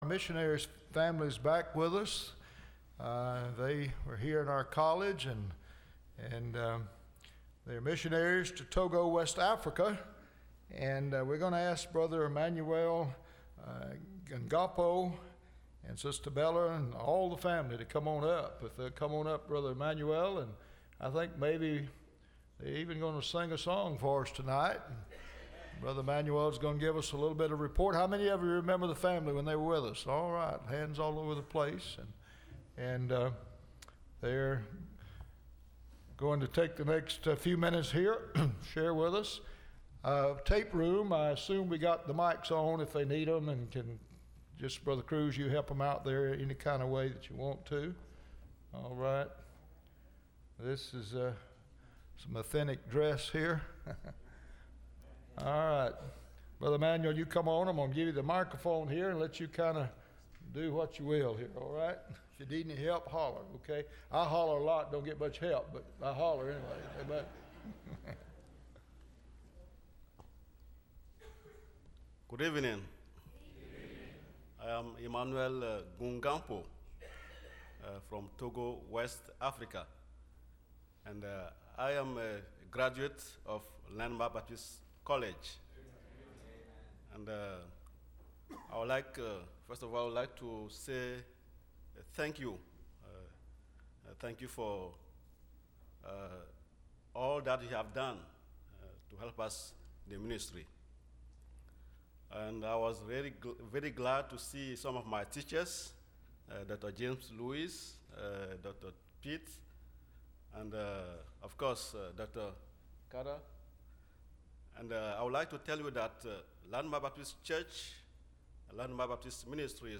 Missionary Testimony – Landmark Baptist Church
Service Type: Wednesday